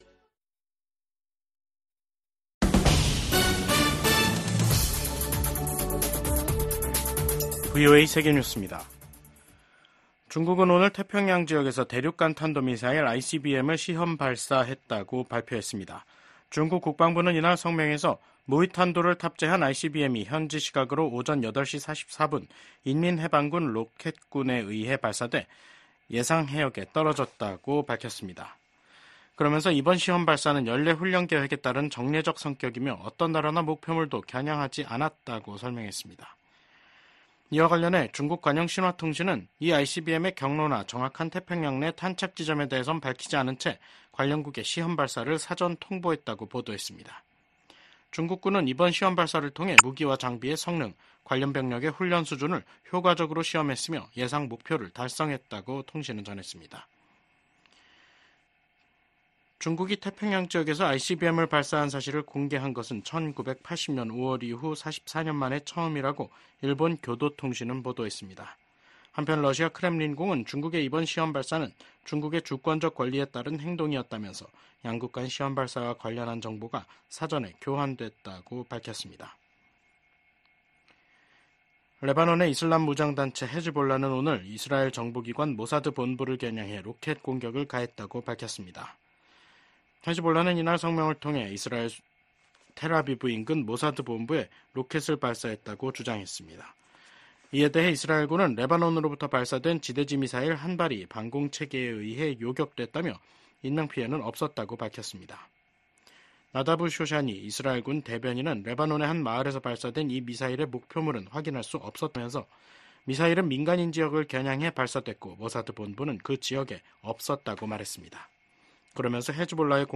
VOA 한국어 간판 뉴스 프로그램 '뉴스 투데이', 2024년 9월 25일 3부 방송입니다. 조 바이든 미국 대통령이 임기 마지막 유엔총회 연설에서 각국이 단합해 전 세계가 직면한 어려움들을 이겨내야 한다고 밝혔습니다. 토니 블링컨 미국 국무장관은 심화되는 북러 군사협력을 강하게 규탄하며 국제사회의 강력한 대응의 필요성을 역설했습니다.